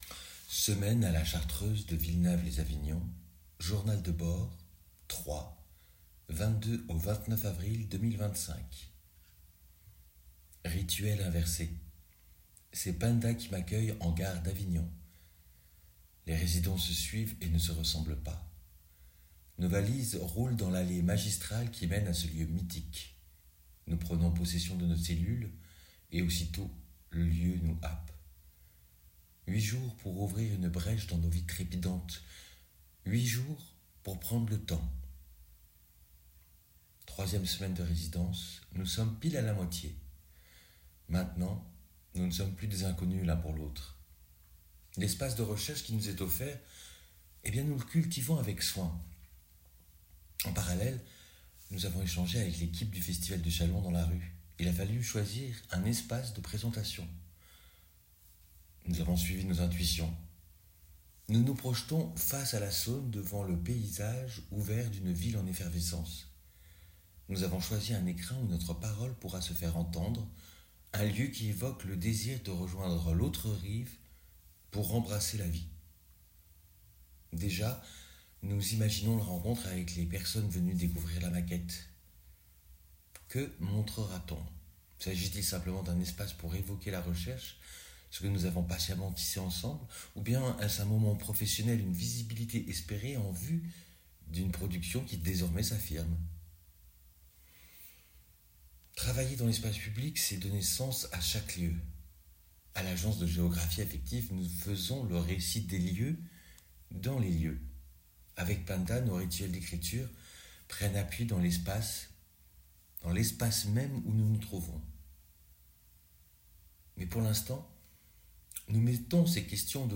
Résidence à La Chartreuse de Villenave Les Avignons
récit sonore